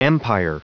Prononciation du mot empire en anglais (fichier audio)
Prononciation du mot : empire